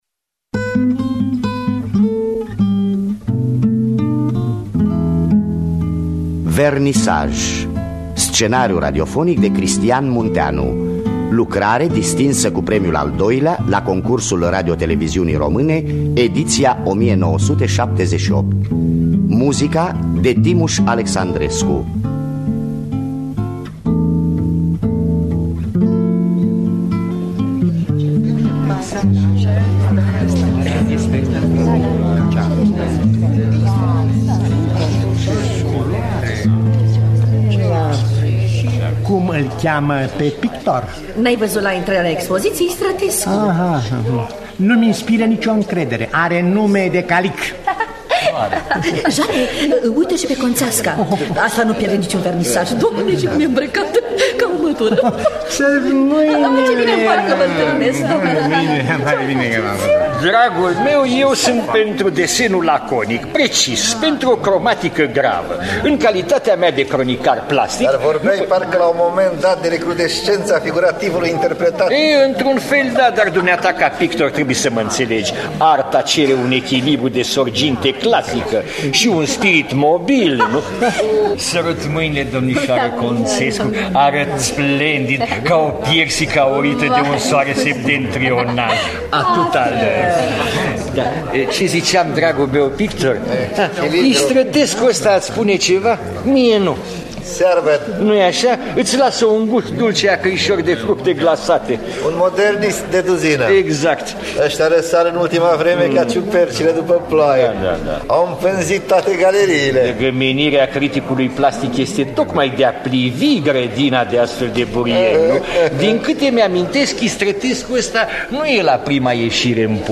Vernisaj de Cristian Munteanu – Teatru Radiofonic Online